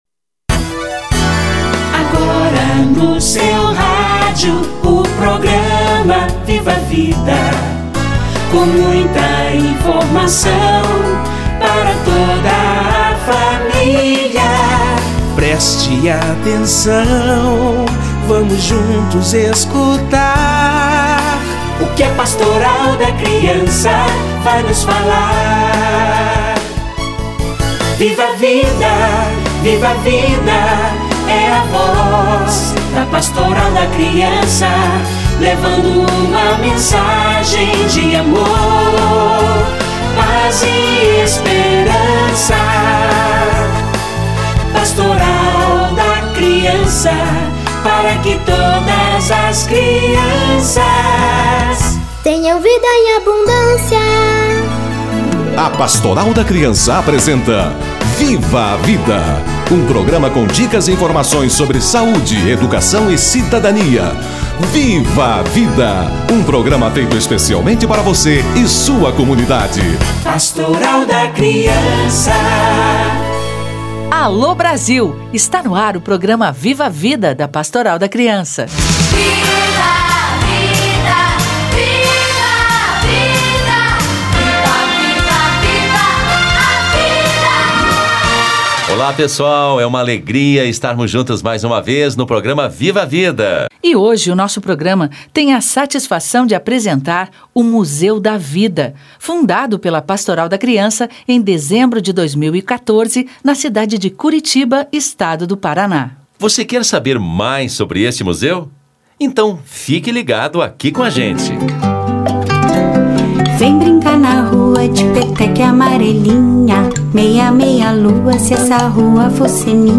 O brincar no Museu da Vida - Entrevista